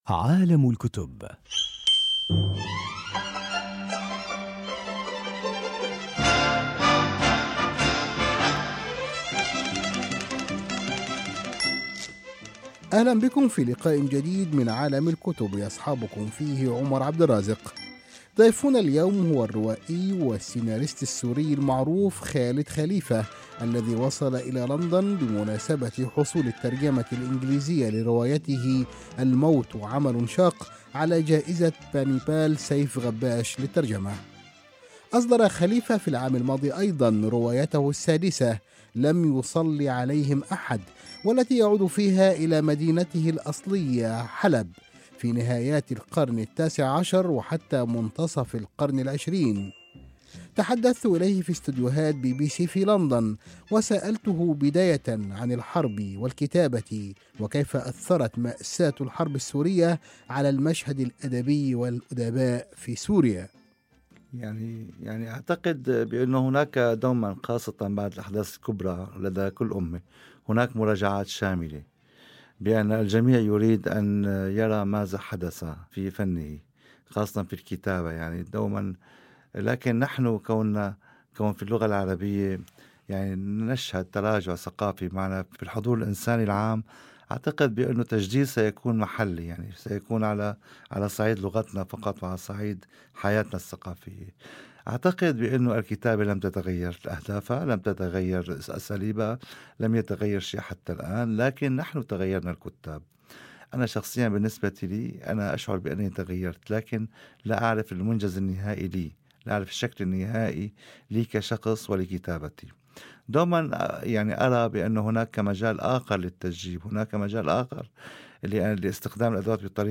عالم الكتب: لقاء مع الروائي السوري خالد خليفة في لندن - خالد خليفة